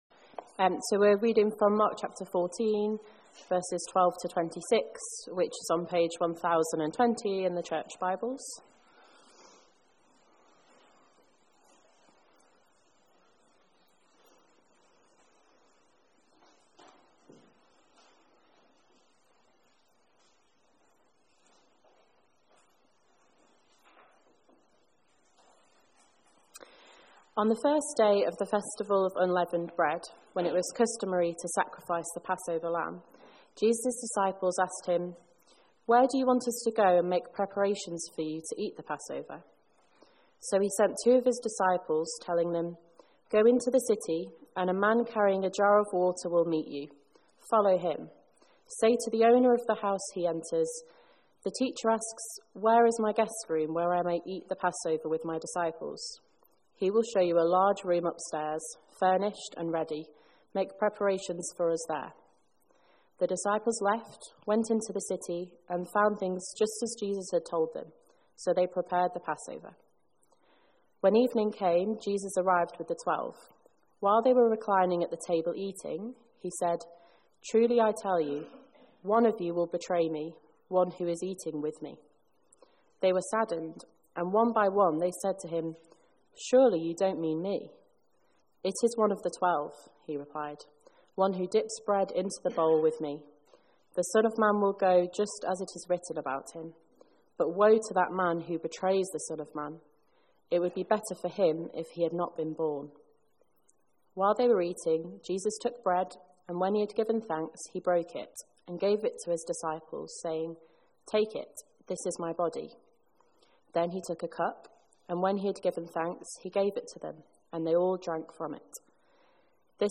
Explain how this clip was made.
"The Riddle of the Bread" (Mark 14:12-26) - Hope Church Tolworth